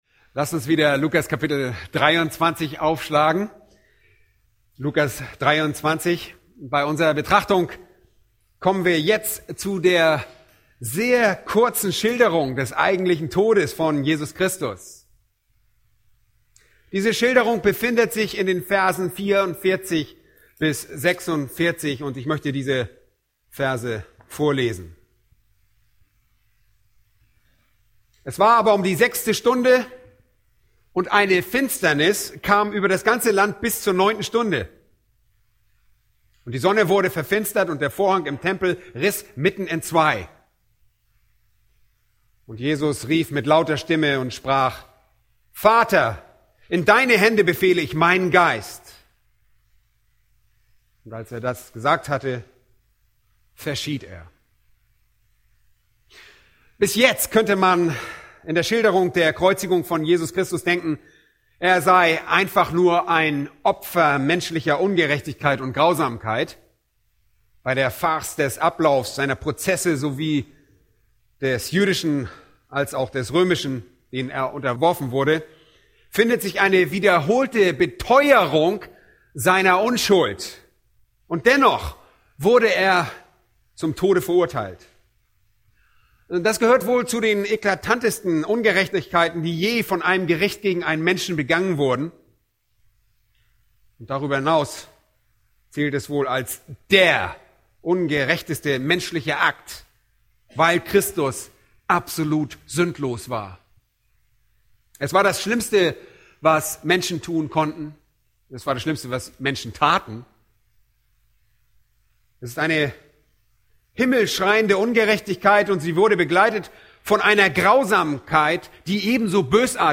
Eine predigt aus der serie "Markus."